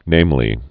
(nāmlē)